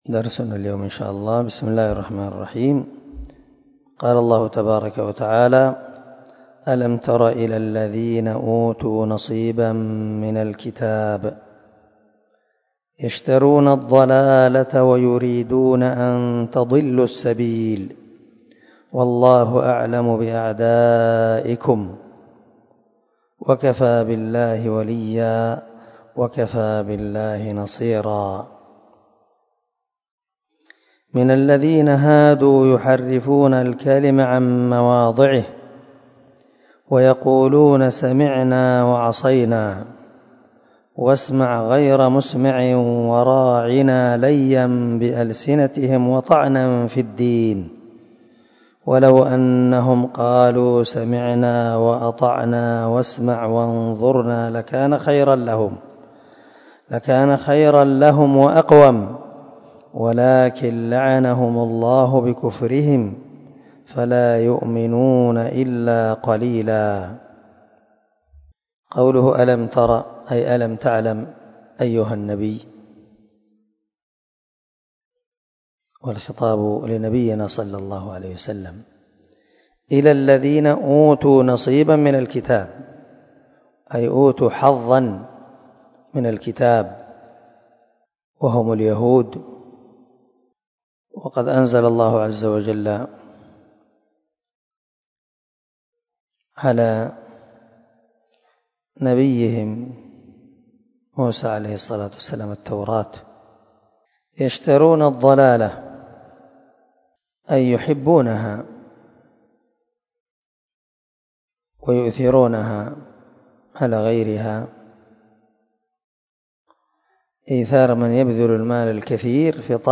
266الدرس 34 تفسير آية ( 44 – 46 ) من سورة النساء من تفسير القران الكريم مع قراءة لتفسير السعدي